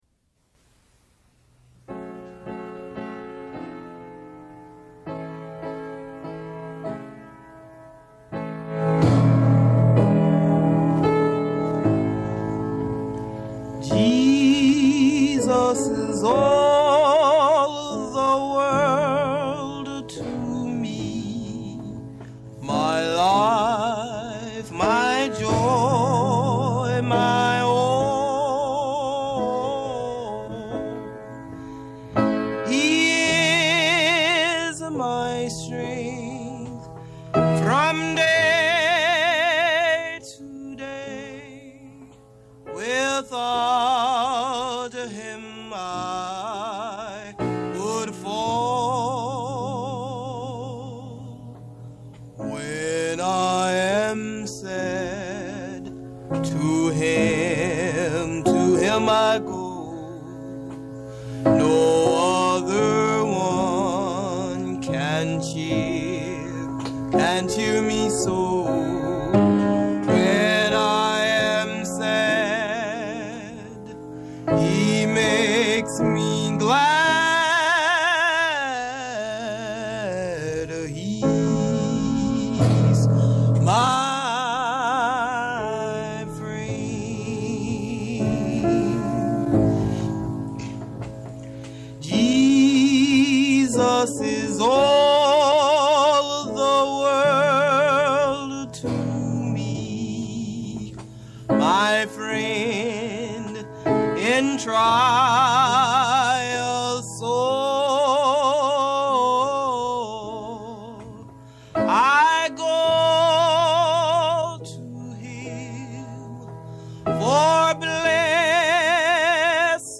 Unidentified gospel performances
There are many starts and stops, as well as fidelity issues.